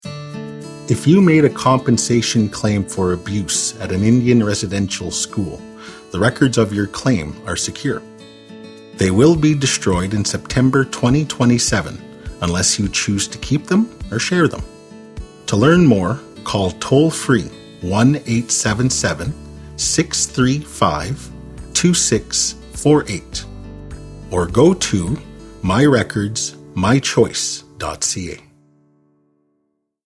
Radio Ads